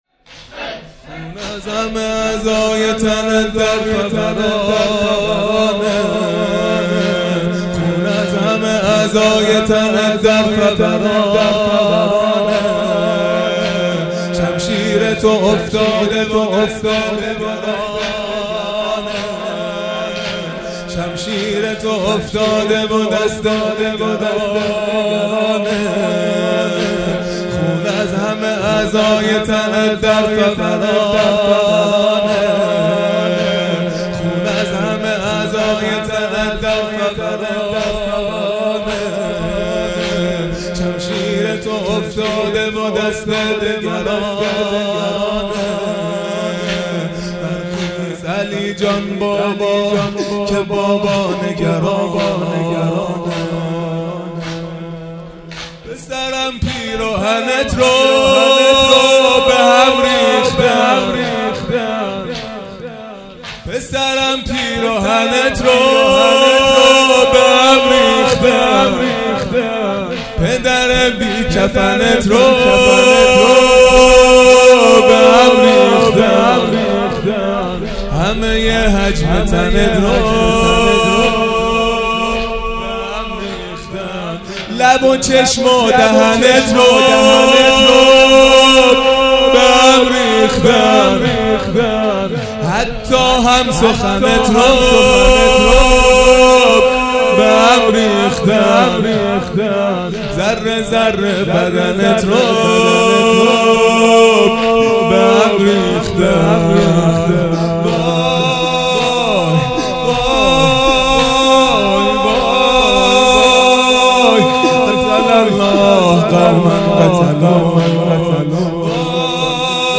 دهه اول محرم الحرام ۱۴۴٢ | شب هشتم